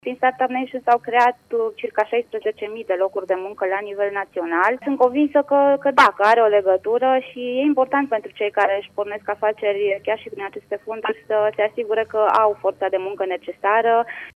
La Brașov, a contribuit la scăderea șomajului și programul Start-Up Nation, aspect confirmat si de deputatul de Brașov Roxana Mînzatu: